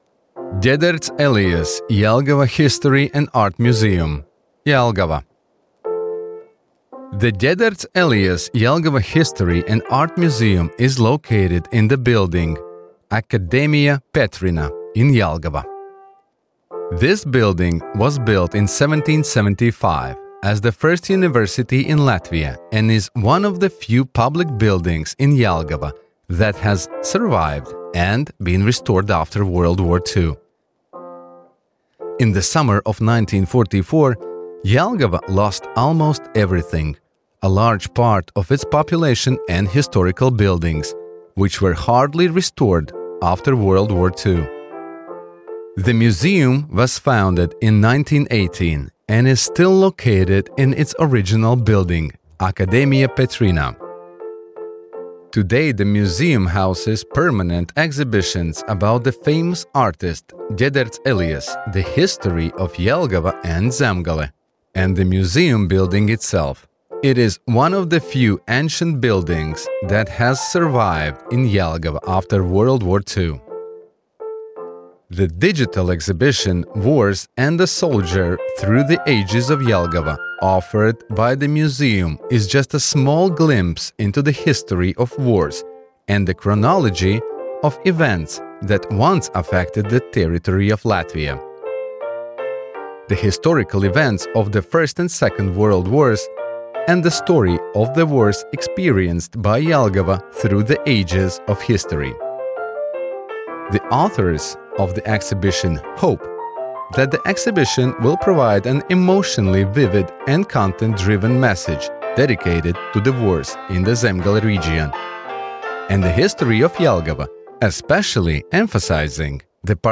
AUDIO GUIDE